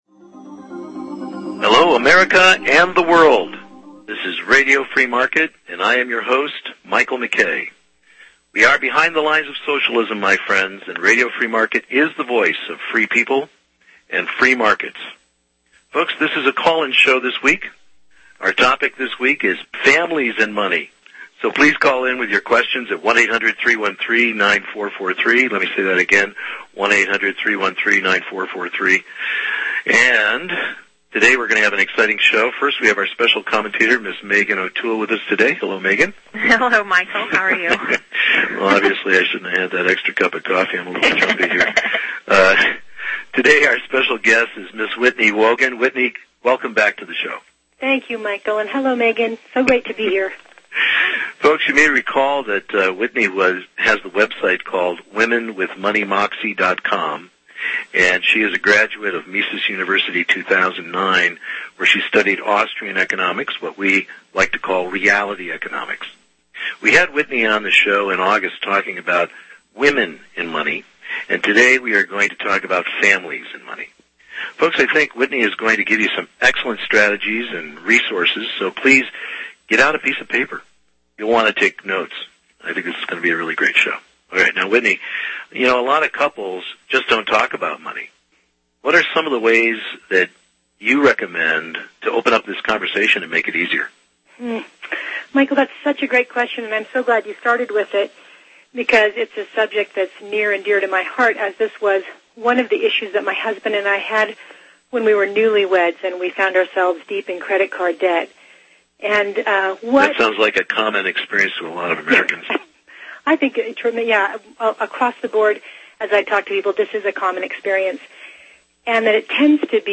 Classic Interview